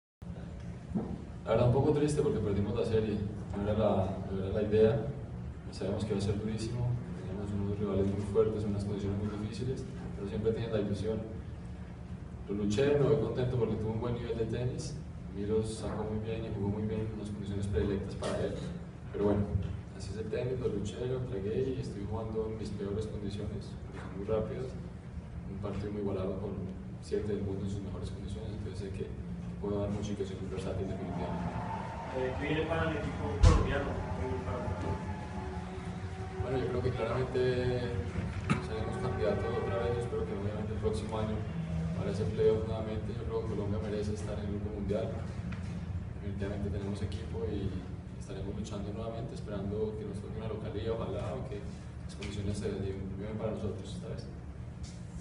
Declaraciones de Santiago Giraldo al término de la serie